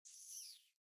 mobs_rat.ogg